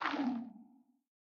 Sculk Sensor sculk clicking stop5.ogg
Sculk_Sensor_sculk_clicking_stop5.ogg